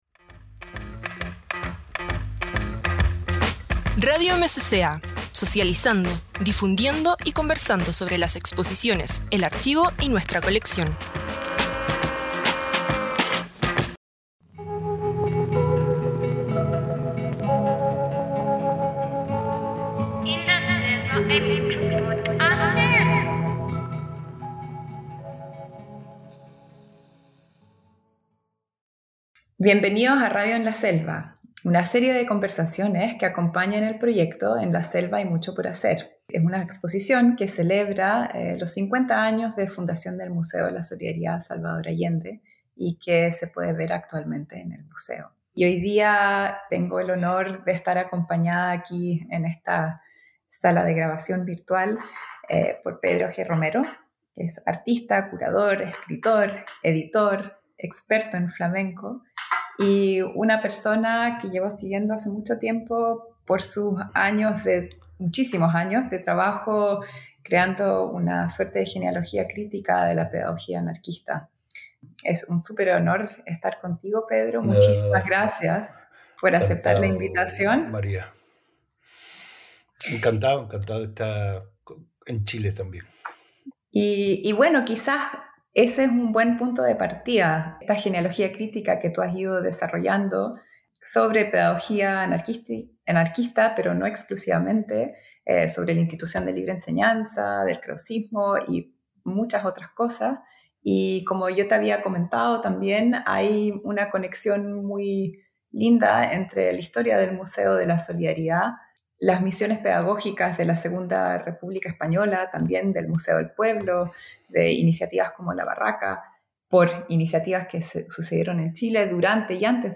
conversa junto al artista e investigador